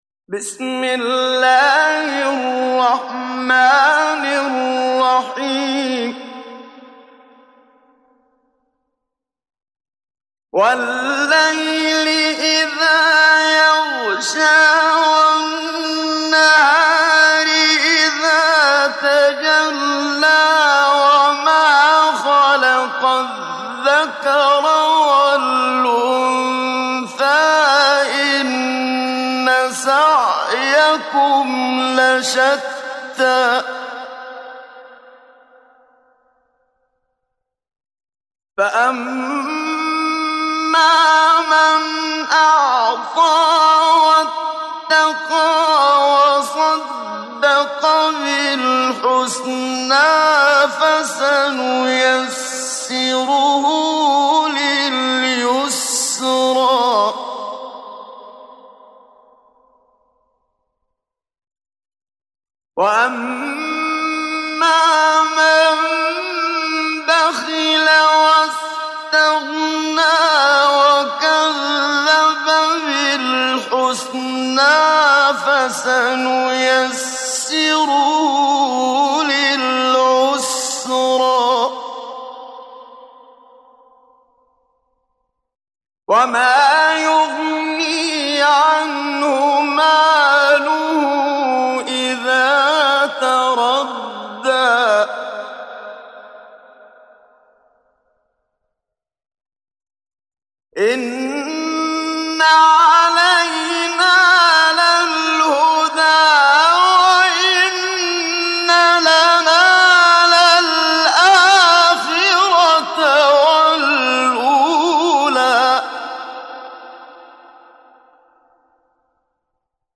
محمد صديق المنشاوي – تجويد